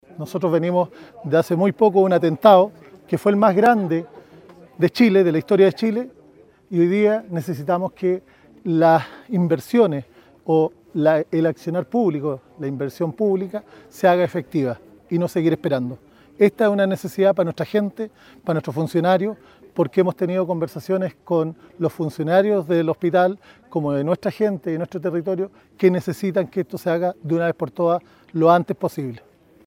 El jefe comunal sostuvo además que “la inversión pública se tiene que hacer efectiva y no seguir esperando”.